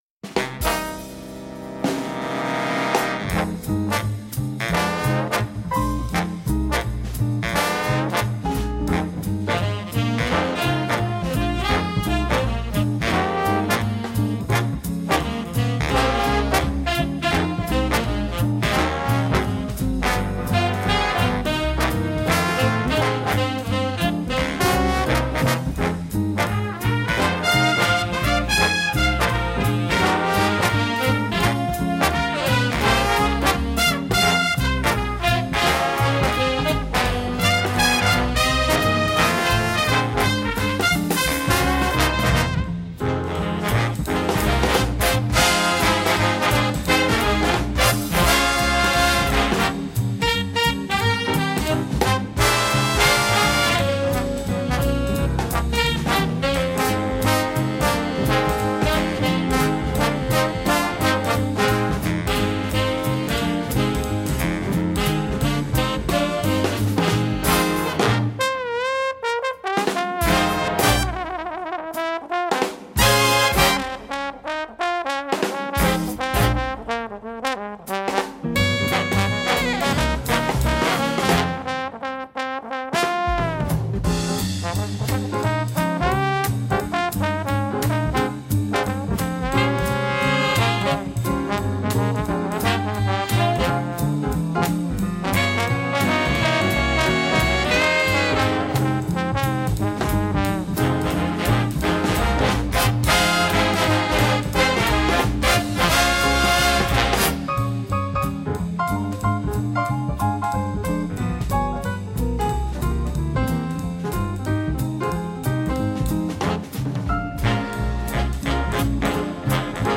Voicing: Jazz Band